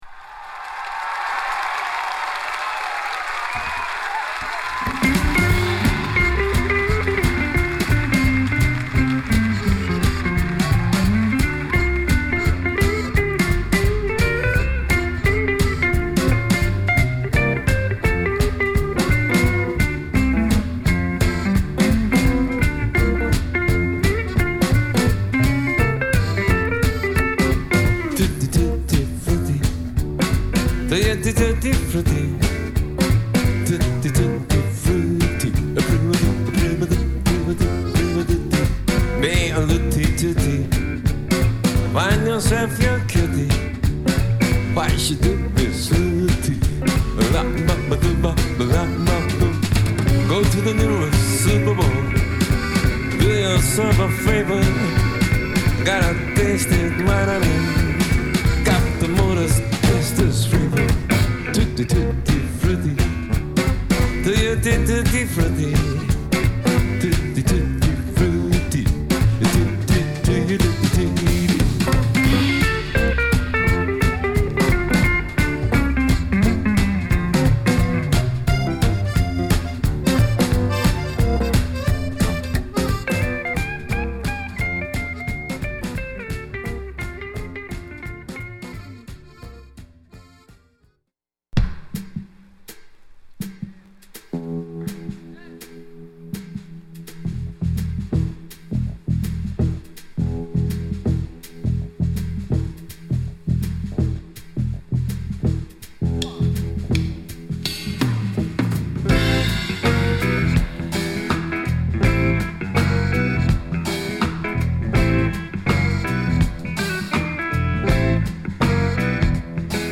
2019年に開催され大盛況となったUSツアーの模様を収録したライブアルバム